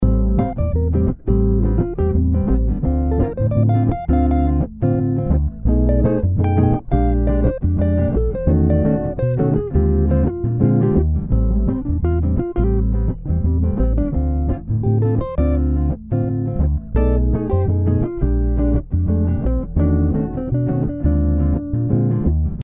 BossaBeat
BossaBeat.mp3